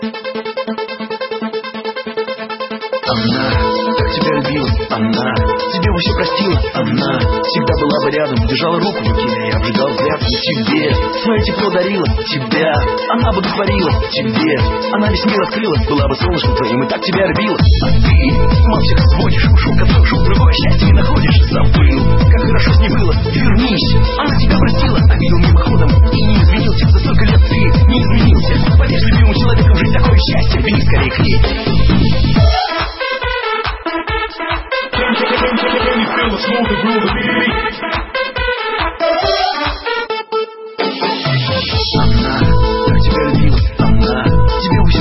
Зона обмена: Музыка | Русская ПОПса